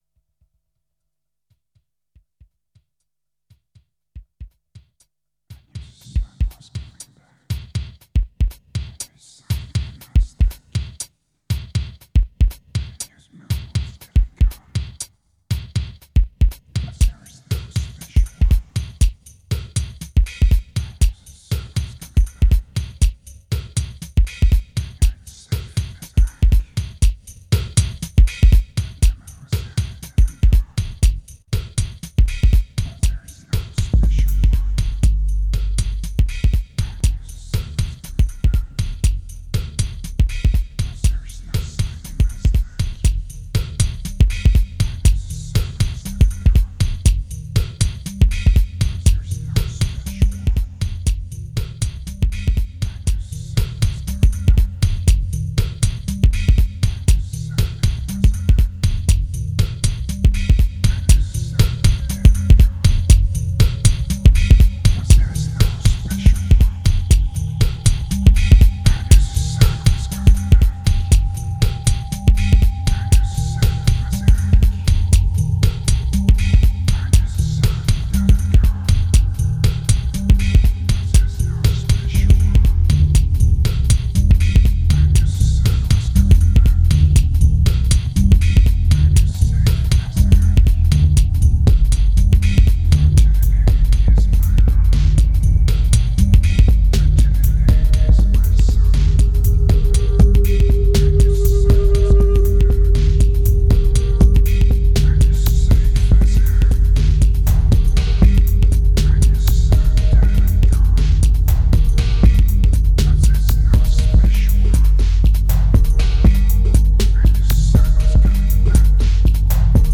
1919📈 - -10%🤔 - 120BPM🔊 - 2010-09-03📅 - -183🌟